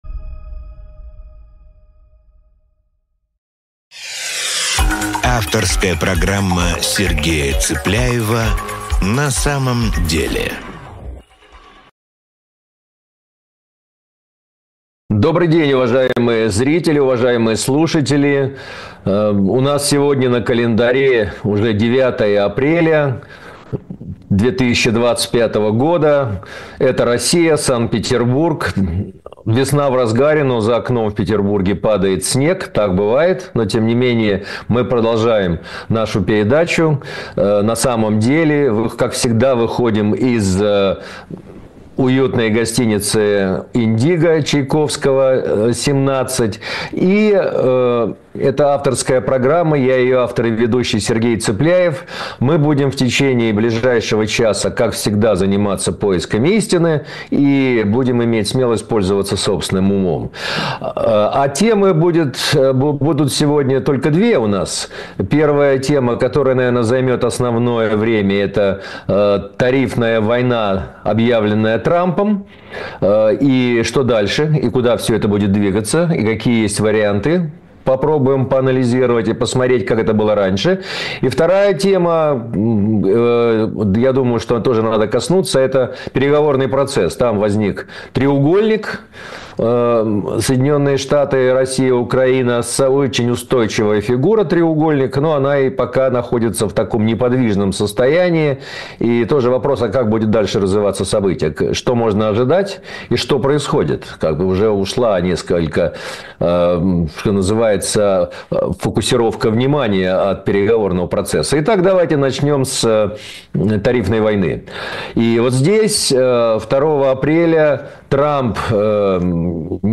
Эфир ведёт Сергей Цыпляев